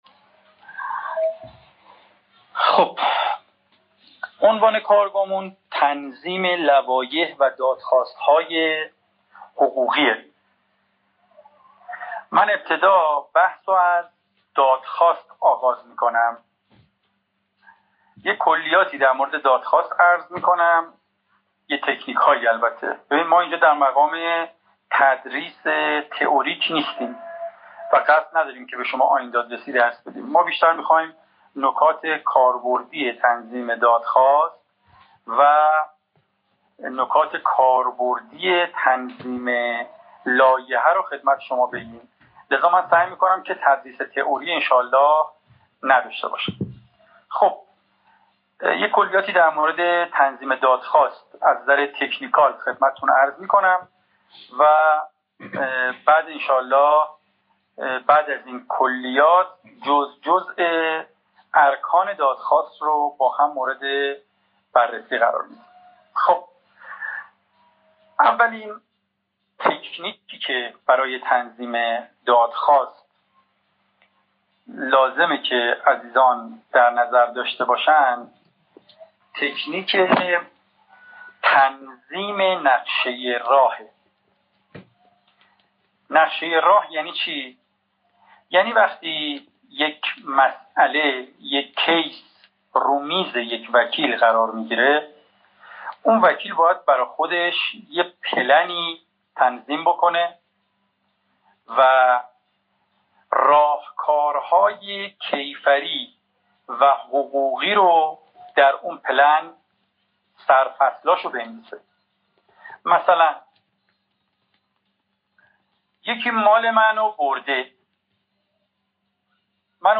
legal-pleading-drafting-workshop-part1.mp3